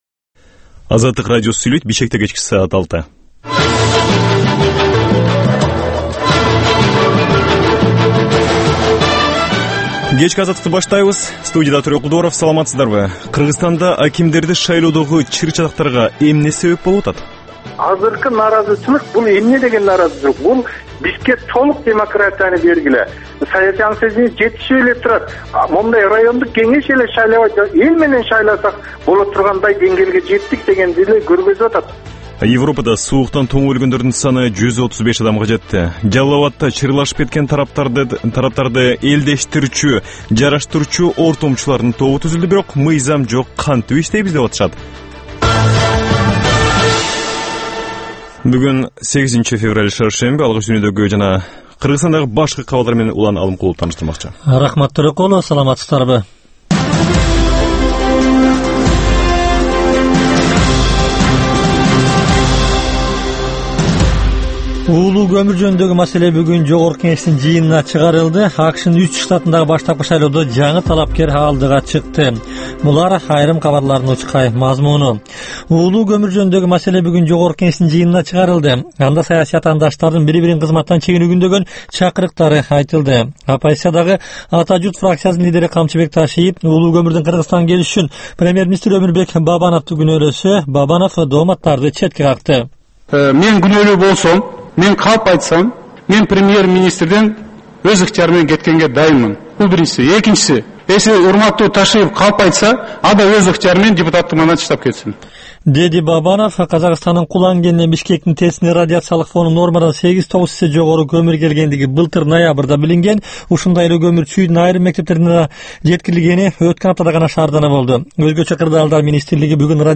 "Азаттык үналгысынын" бул кечки алгачкы жарым сааттык берүүсү жергиликтүү жана эл аралык кабарлар, репортаж, маек, аналитикалык баян, сереп, угармандардын ой-пикирлери, окурмандардын э-кат аркылуу келген пикирлеринин жалпыламасы жана башка берүүлөрдөн турат. Бул үналгы берүү ар күнү Бишкек убакыты боюнча саат 18:00ден 18:30га чейин обого түз чыгат.